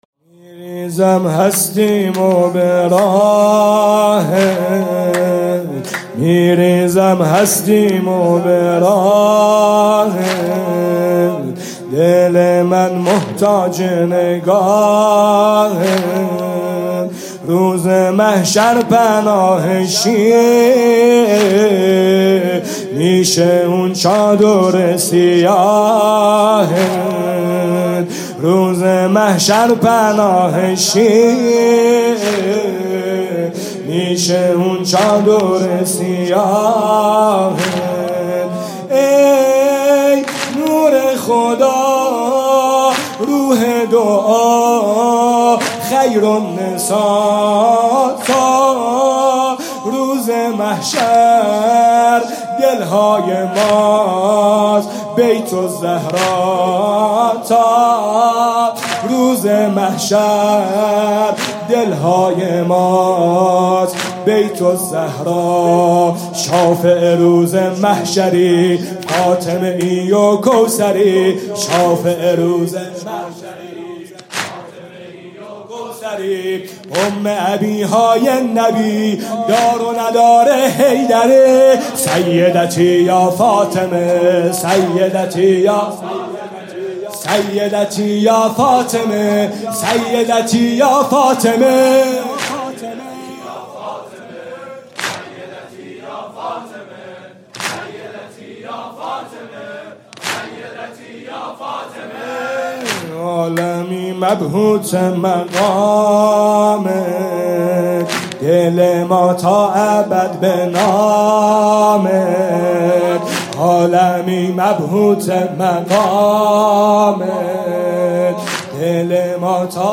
شب اول فاطمیه دوم ۱۴۰۴
music-icon شور: از عشق عاشقای تو سرشارم